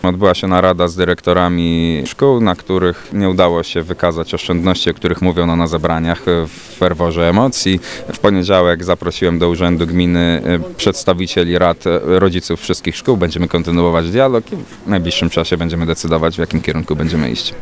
Podczas spotkań wójta Wadowic Górnych z mieszkańcami tychże miejscowości proponowano by szukać środków finansowych w zarządzaniu placówek szkolnych, niestety takich nie udało się znaleźć, tłumaczy wójt Michał Deptuła.